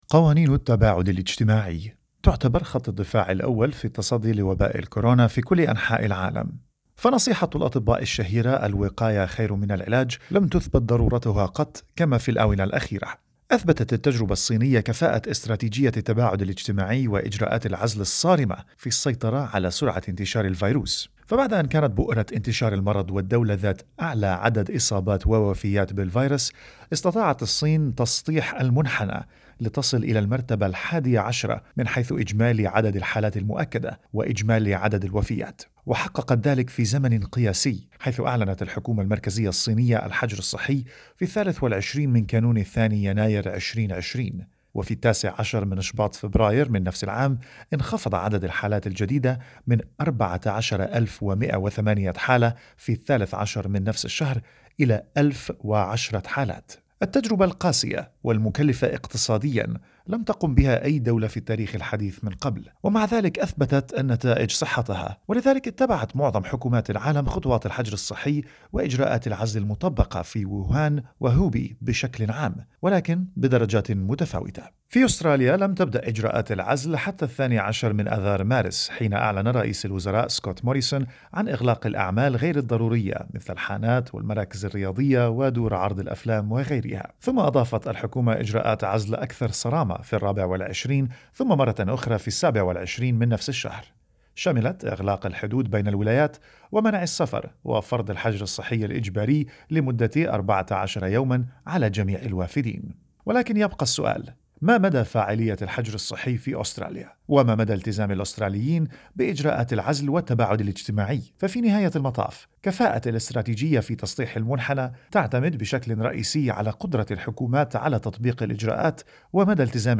التزام الجالية العربية في أستراليا في ظل سرعة تغير الإجراءات تحدثنا مع أفراد من الجالية العربية في أستراليا و سألناهم عن ما شاهدوه من خروقات لإجراءات العزل في حياتهم اليومية.